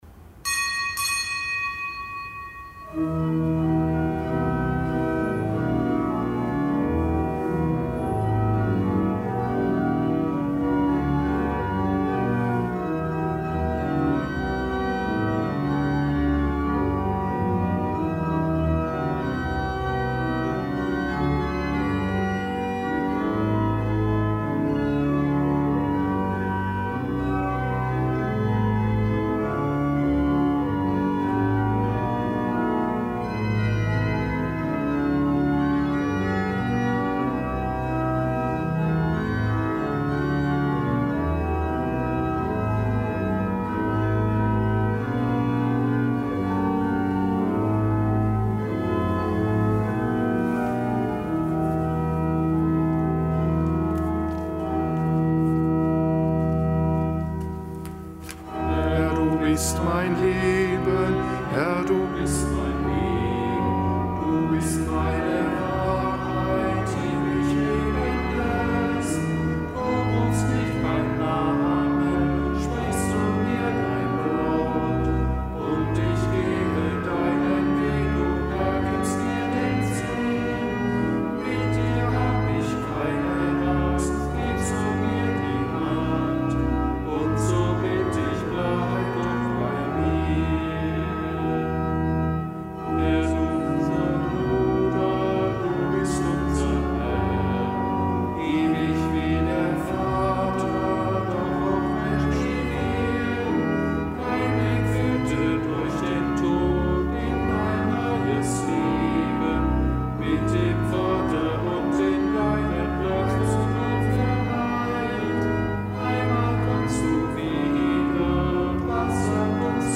Kapitelsmesse am Gedenktag des Heiligen Johannes Bosco
Kapitelsmesse aus dem Kölner Dom am Gedenktag des Heiligen Johannes Bosco, Priester, Ordensgründer.